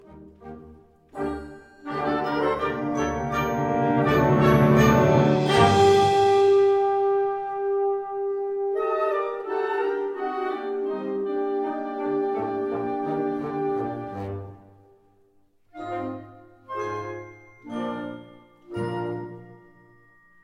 Gattung: Solo für Tuba und Blasorchester
Besetzung: Blasorchester
Solo für Tuba nach der bekannten Melodie für Klarinette.